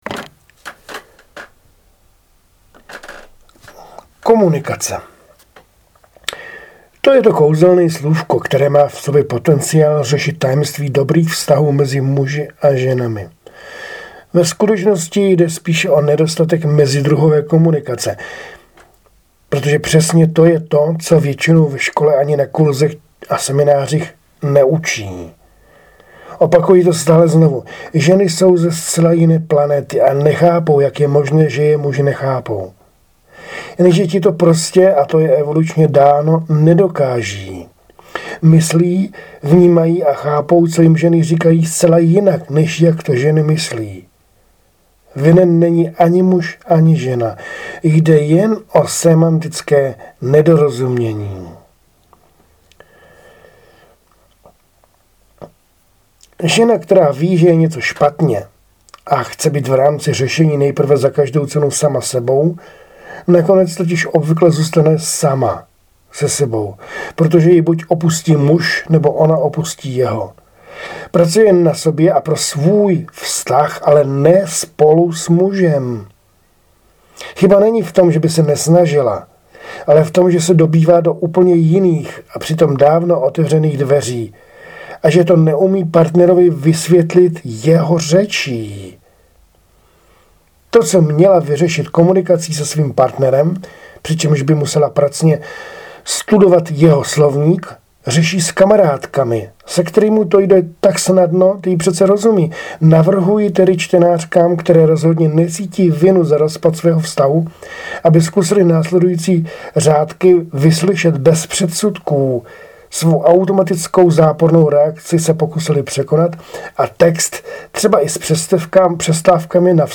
Autorsky namluvený fejeton